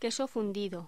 Locución: Queso fundido
voz
Sonidos: Voz humana